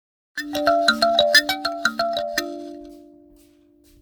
カリンバSS
親指でバーをはじくと優しい音色で心が和みます。
共鳴体は木・ひょうたん・空き缶などを使い、「ビリビリジャラジャラ」と心地よい癒しサウンドで人々を魅了します。
こちらの商品はチューニングしてありません。